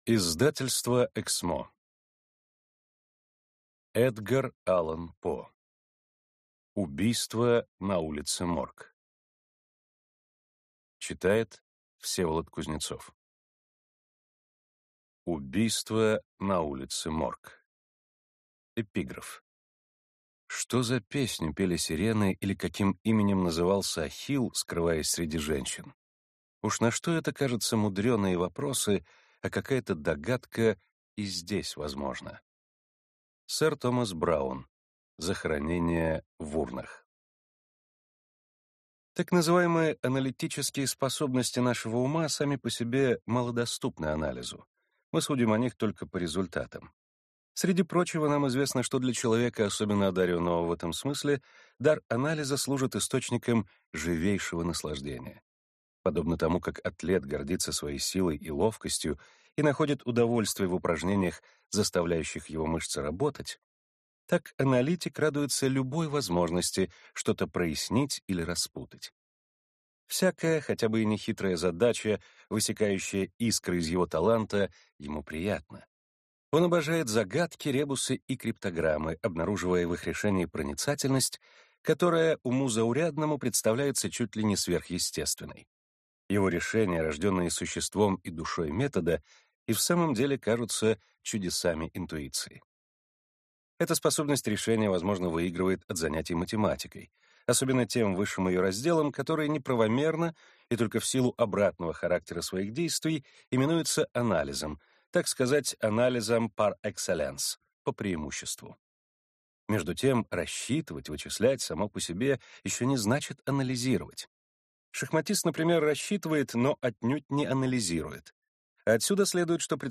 Аудиокнига Убийство на улице Морг | Библиотека аудиокниг